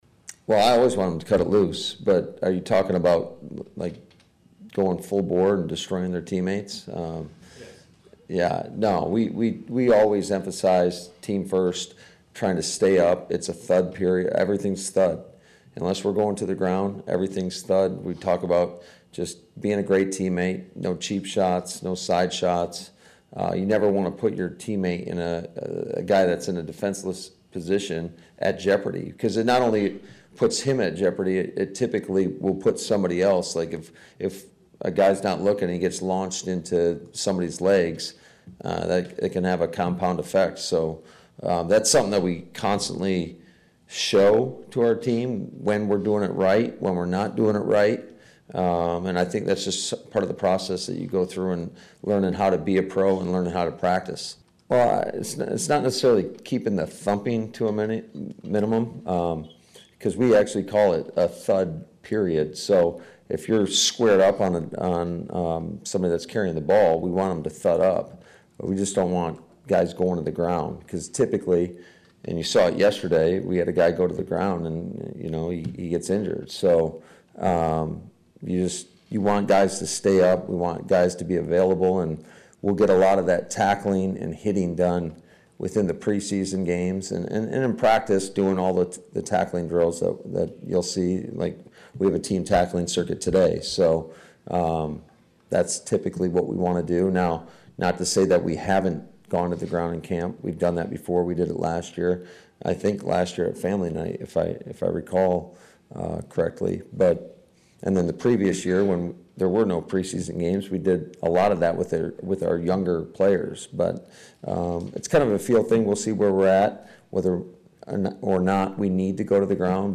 Before practice, Head Coach Matt LaFleur said while the gear is on for the first time, the evaluation emphasis hasn’t changed, he wants the best from every snap.